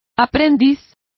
Complete with pronunciation of the translation of apprentices.